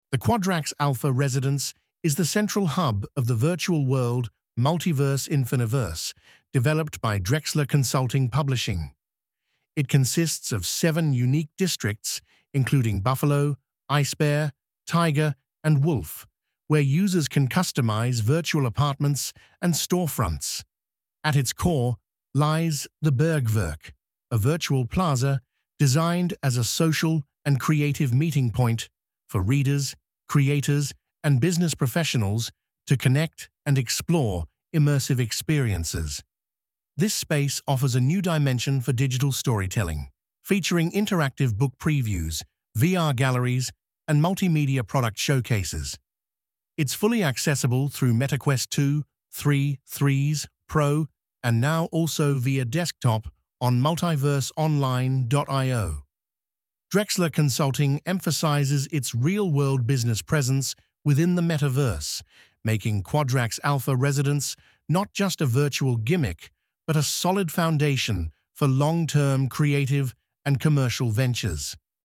Audio summary of Quadrax Alpha Residence Virtual Reality life.